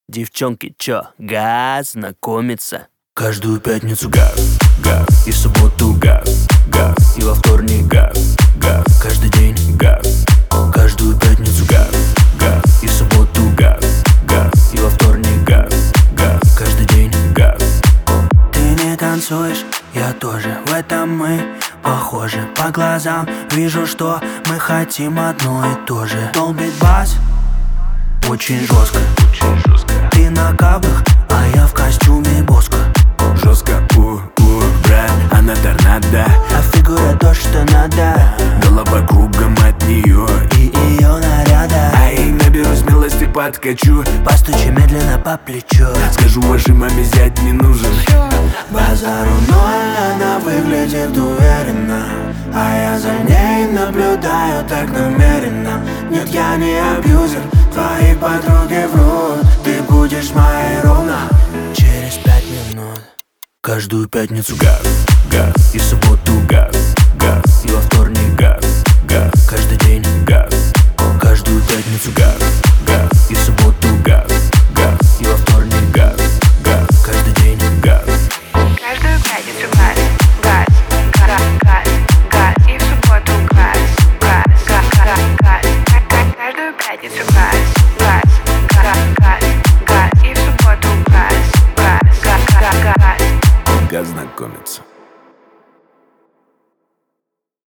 Лаунж